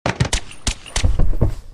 Play, download and share AssassinationSilencerSFX original sound button!!!!
assassinationsilencersfx.mp3